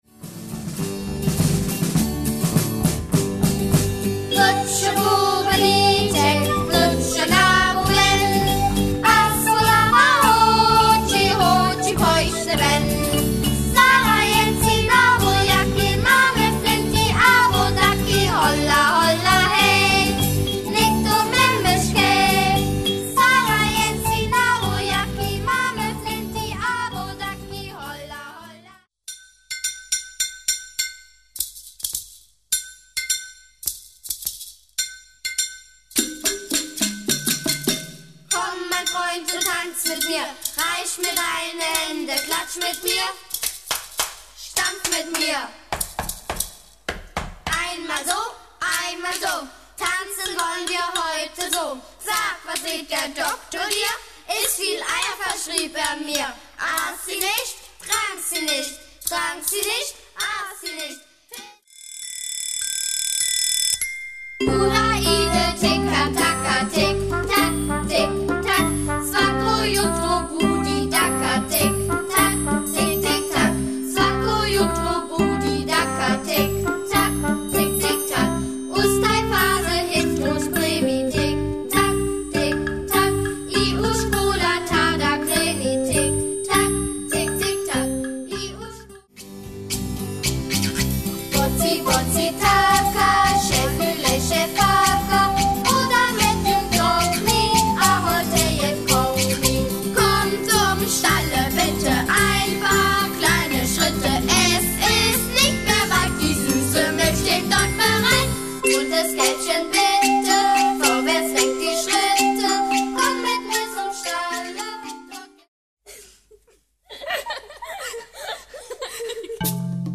für Kinder ab 6 Jahren.